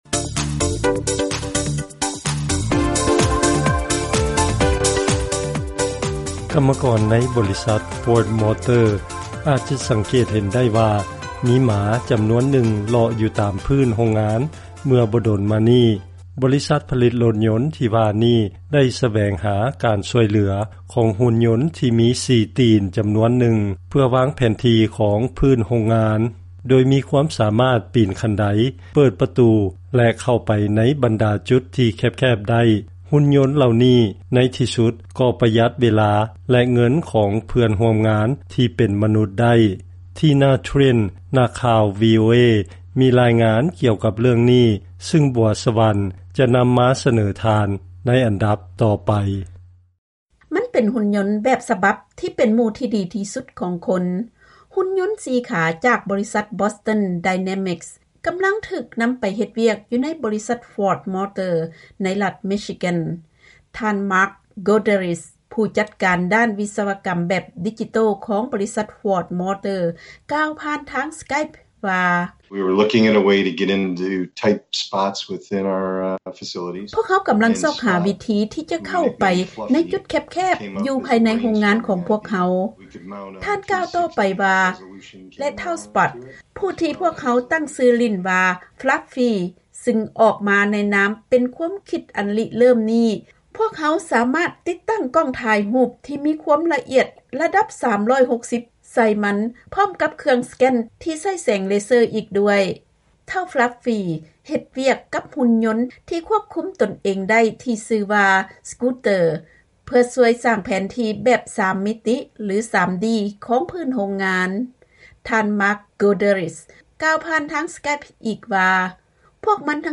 ເຊີນຟັງລາຍງານກ່ຽວກັບໝາຫຸ່ນຍົນສາມາດເຮັດວຽກເກັບກໍາຂໍ້ມູນໄດ້